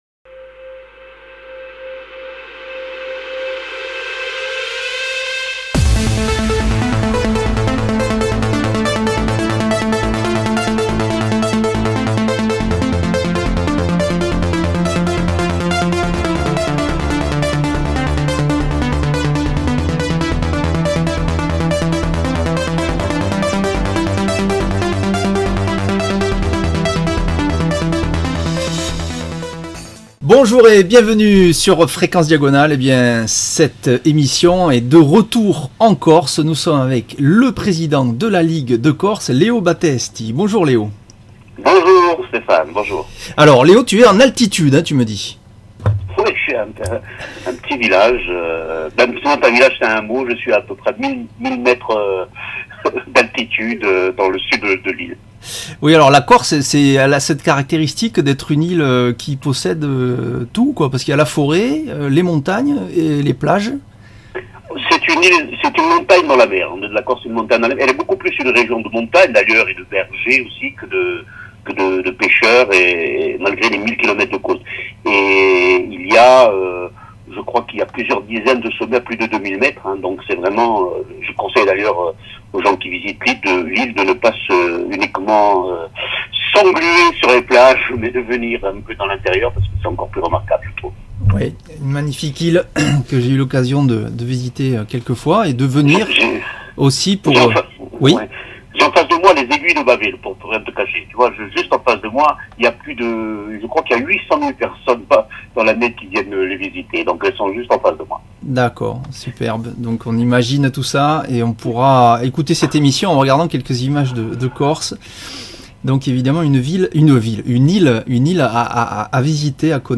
1ere partie Interview : le Corsican Circuit, la ligue corse, la formation